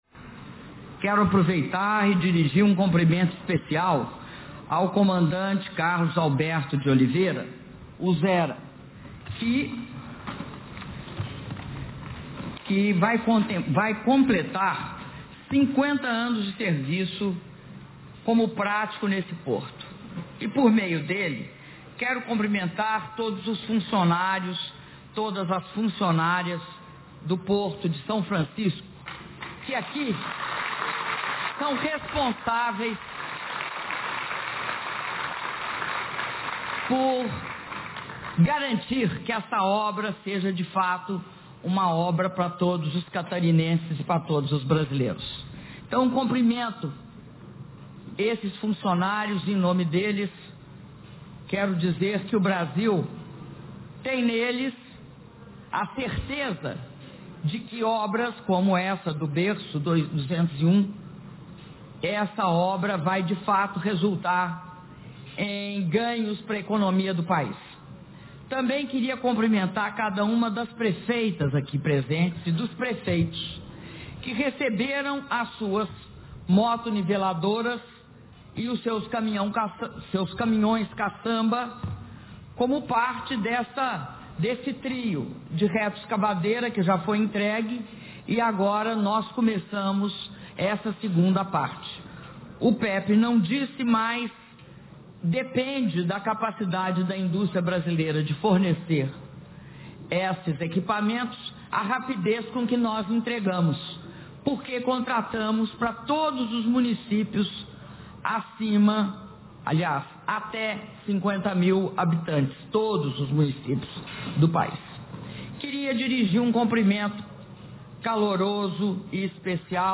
Áudio do discurso da Presidenta da República, Dilma Rousseff, durante cerimônia de inauguração do berço 201 e do Porto de São Francisco do Sul - São Francisco do Sul/SC